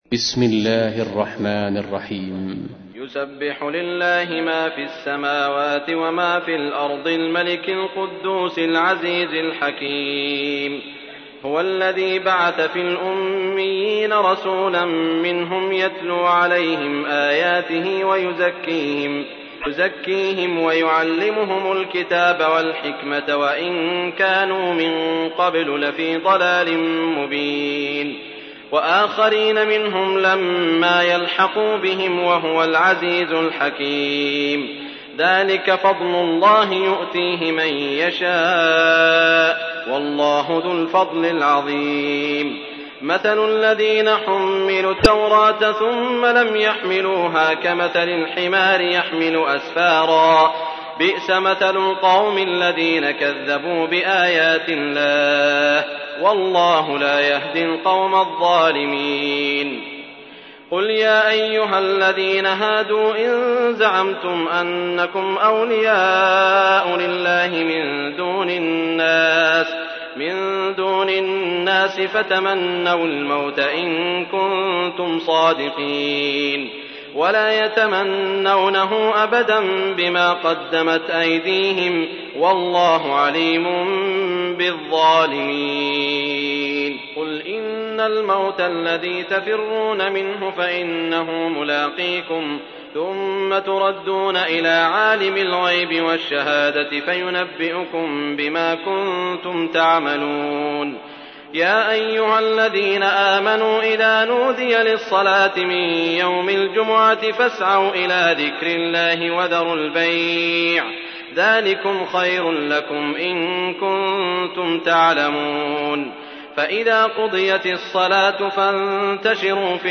تحميل : 62. سورة الجمعة / القارئ سعود الشريم / القرآن الكريم / موقع يا حسين